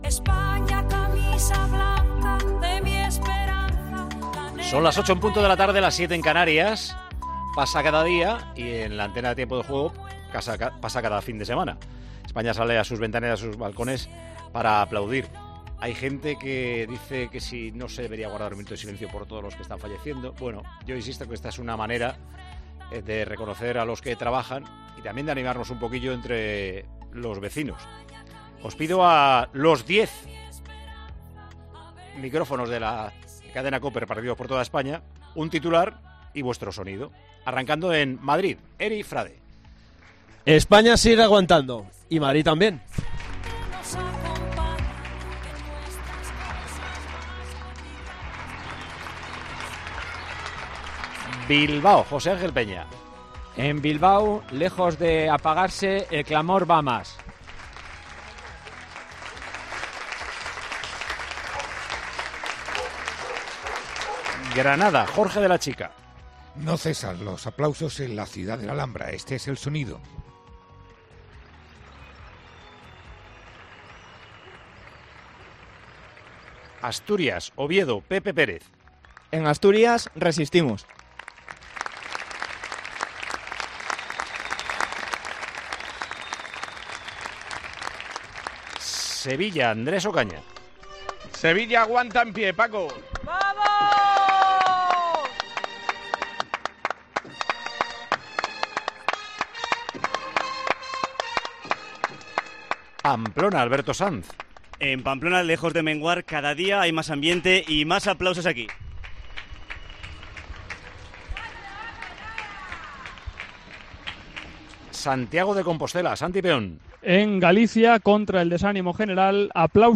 Ronda de aplausos en Tiempo de Juego: sábado, 11 de abril de 2020
Tiempo de Juego se asoma a los balcones de España cuando se cumplen cuatro semanas desde la declaración del Estado de Alarma.